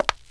CONCRETE L3.WAV